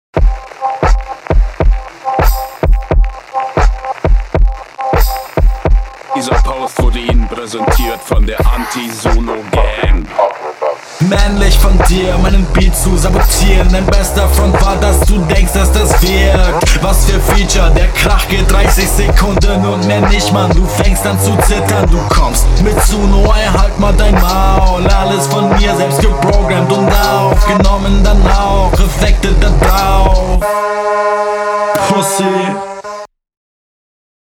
Geil geflowt bro